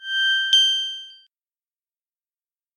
Звуки загрузки файла